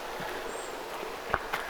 yllättävän korkea nuoren
keltanokkarastaan ääni
keltanokkarastaslinnun_yllattavan_korkea_aani.mp3